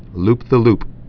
(lpthə-lp) or loop-de-loop (-də-)